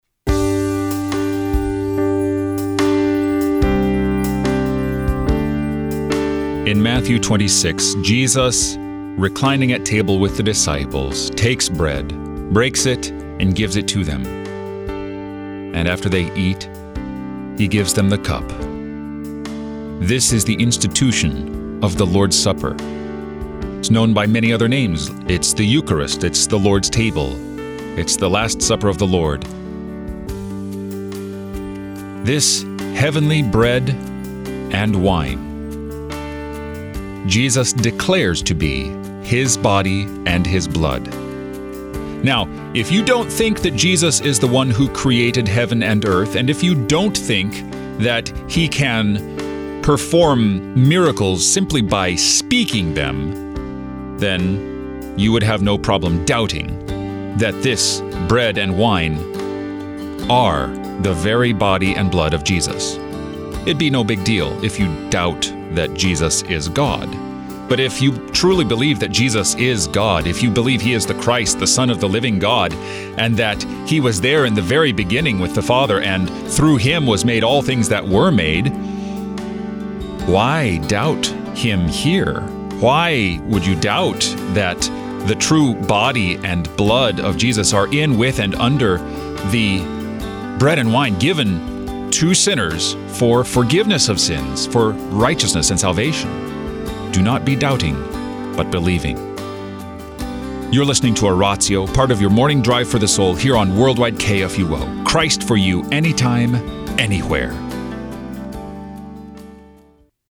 who shares scripture, hymns, prayers, and texts for the day, and also gives a short meditation on the day’s scripture lessons.